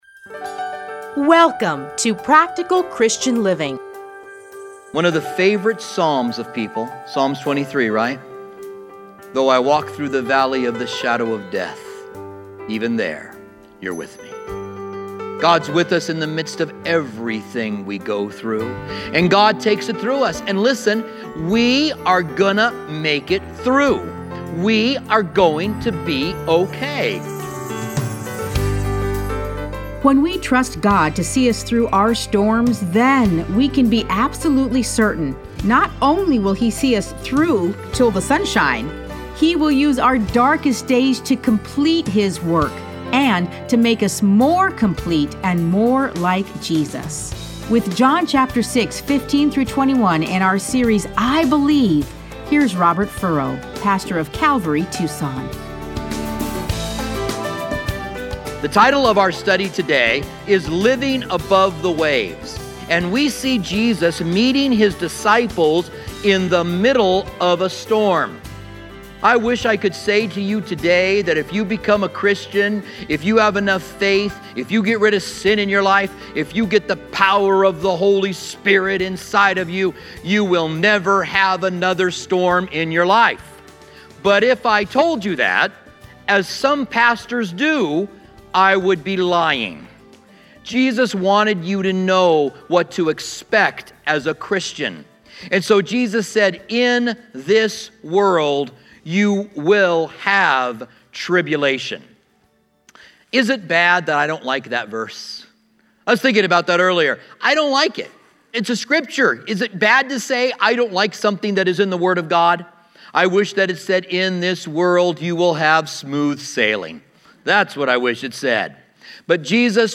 Listen to a teaching from John 6:15-21.